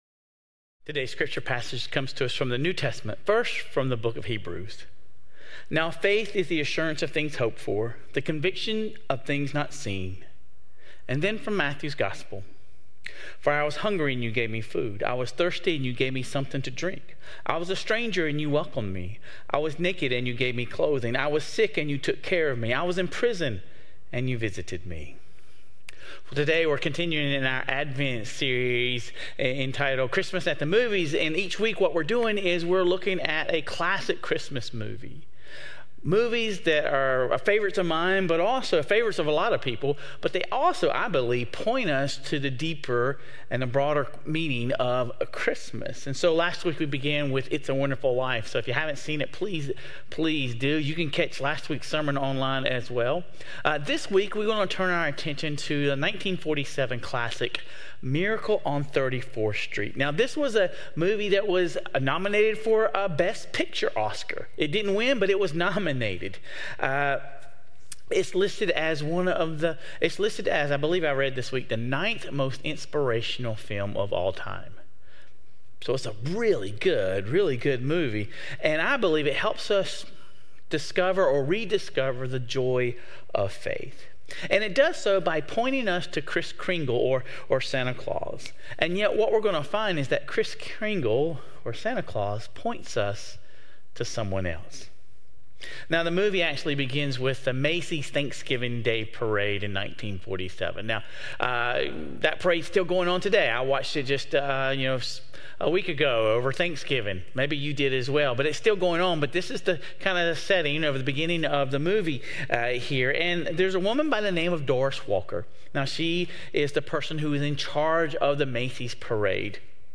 Sermon Reflections: How does the portrayal of Doris and Fred in "Miracle on 34th Street" reflect the tension between viewing the world through logic and reason versus embracing a deeper, less tangible truth?